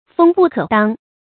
鋒不可當 注音： ㄈㄥ ㄅㄨˋ ㄎㄜˇ ㄉㄤ 讀音讀法： 意思解釋： 鋒：鋒芒，指刀劍的刃和尖；當：阻擋、承受。